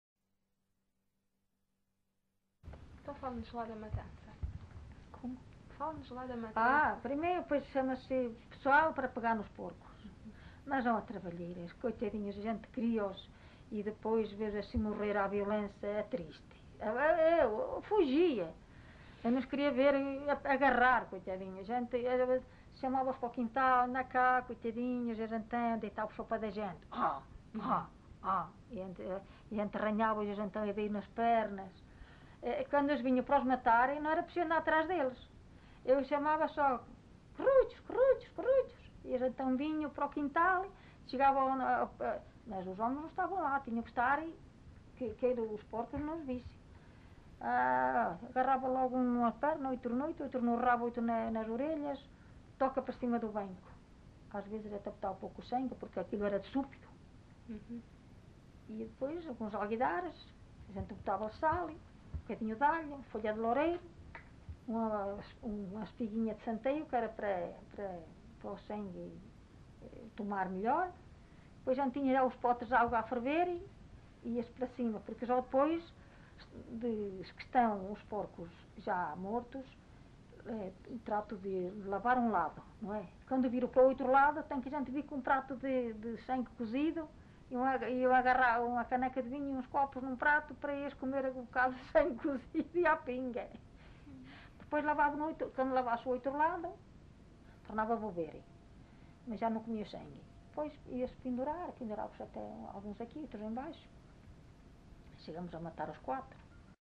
LocalidadePerafita (Alijó, Vila Real)